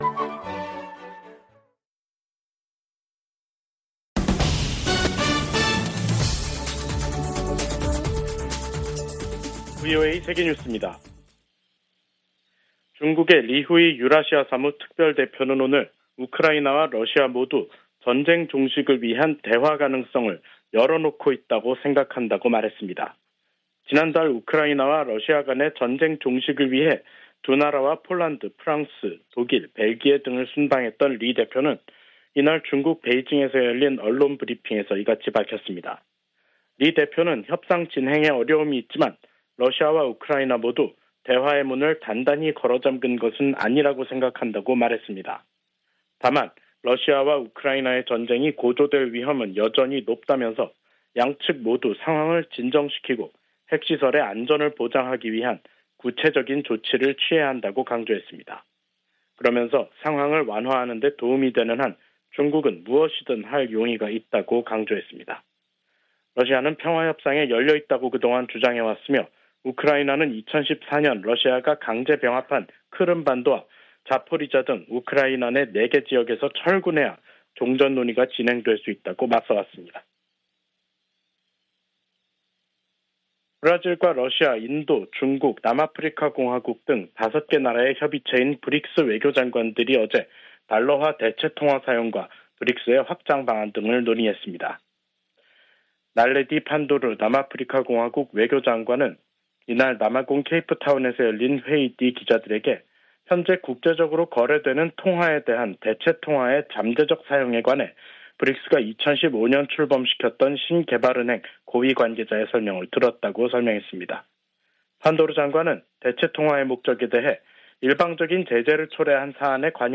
VOA 한국어 간판 뉴스 프로그램 '뉴스 투데이', 2023년 6월 2일 2부 방송입니다. 유엔 안보리가 미국의 요청으로 북한의 위성 발사에 대한 대응 방안을 논의하는 공개 회의를 개최합니다. 미국과 한국 정부가 북한 해킹 조직 '김수키'의 위험성을 알리는 합동주의보를 발표했습니다.